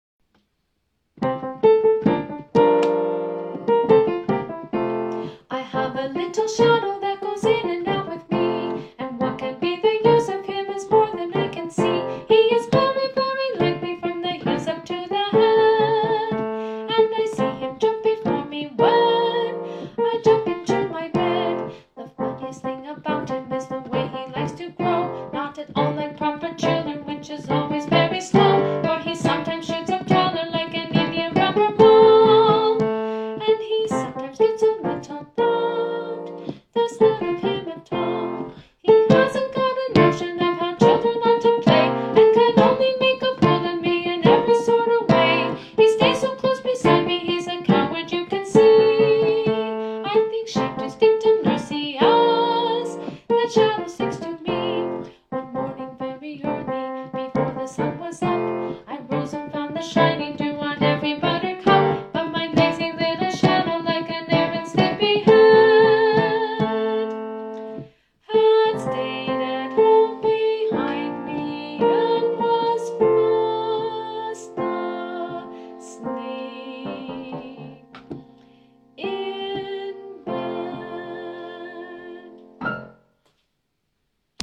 Vocal Solo and Piano
My-Shadow-Vocal-Solo-and-Piano-1.mp3